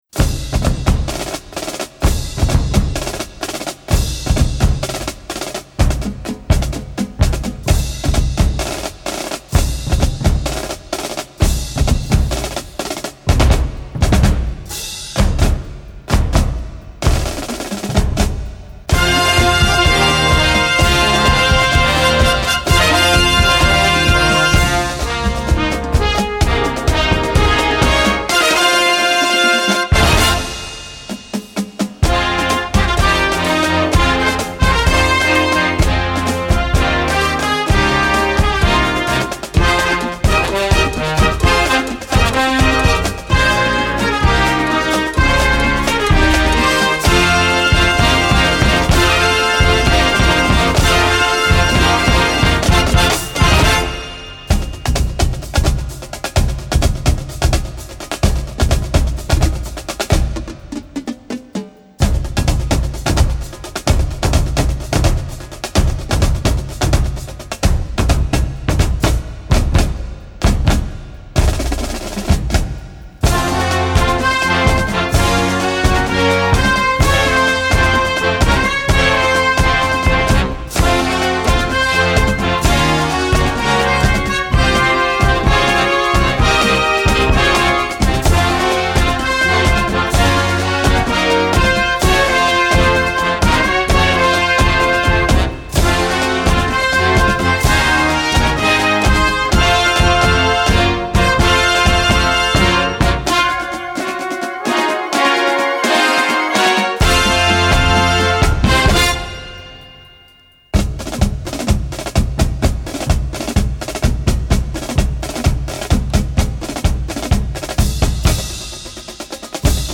Gattung: Medley
Marching-Band
Besetzung: Blasorchester